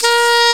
Index of /m8-backup/M8/Samples/Fairlight CMI/IIX/REEDS
TENRSAX.WAV